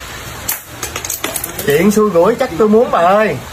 meme sound